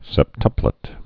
(sĕp-tŭplĭt)